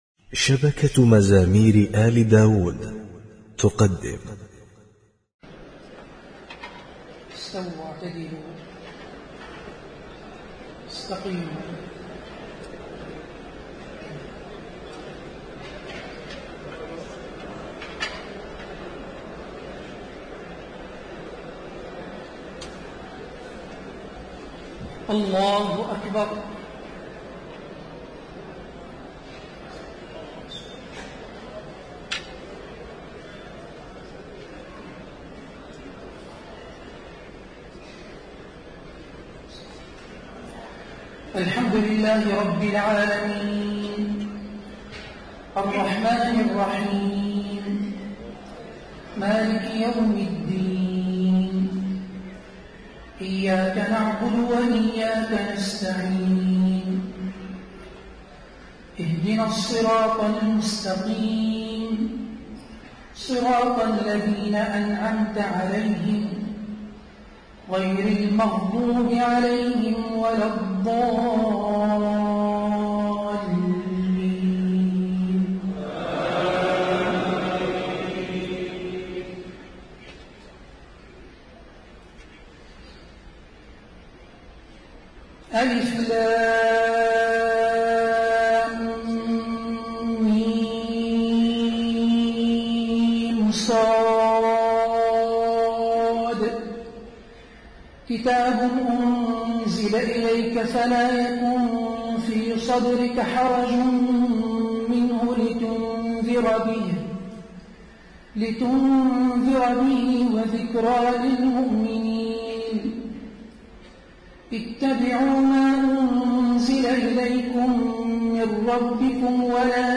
تهجد ليلة 27 رمضان 1432هـ من سورة الأعراف (1-84) Tahajjud 27 st night Ramadan 1432H from Surah Al-A’raf > تراويح الحرم النبوي عام 1432 🕌 > التراويح - تلاوات الحرمين